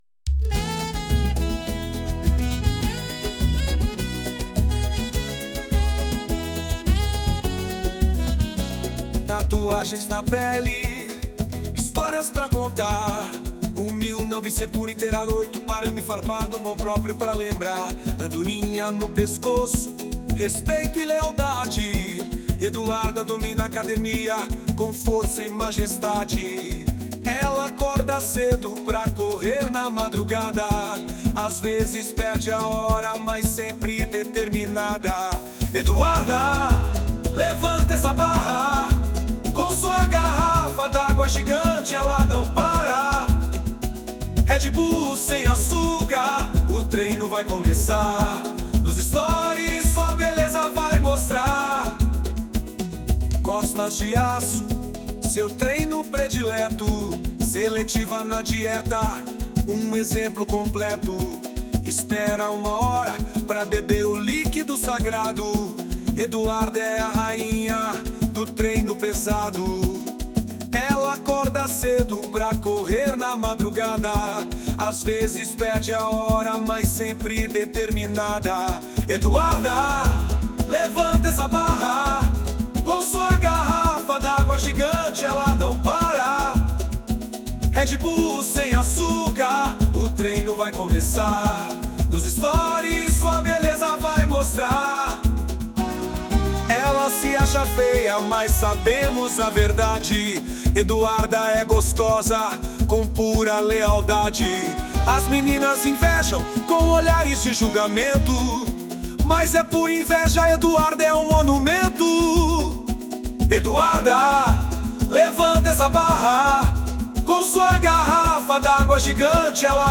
Versão Pagode 2